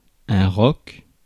Ääntäminen
Synonyymit (shakki) tour roche Ääntäminen France: IPA: /ʁɔk/ Haettu sana löytyi näillä lähdekielillä: ranska Käännös 1. cкала (skala) 2. ка́мък {m} Suku: m .